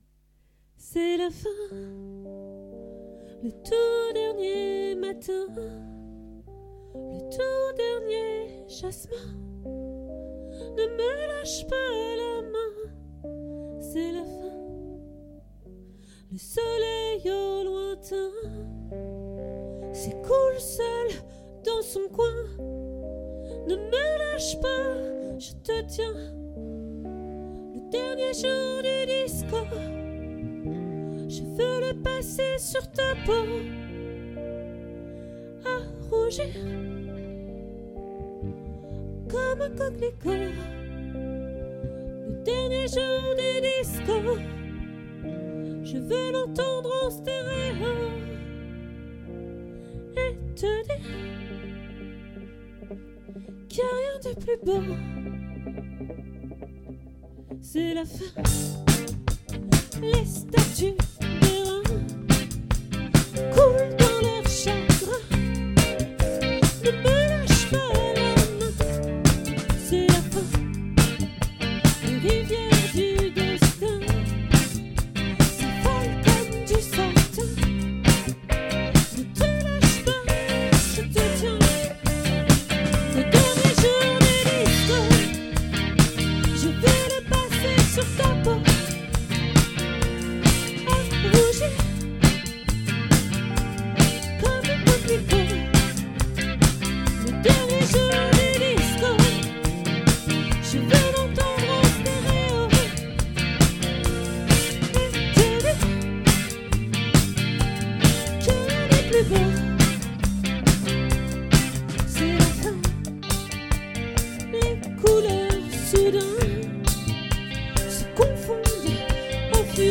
🏠 Accueil Repetitions Records_2023_06_14_OLVRE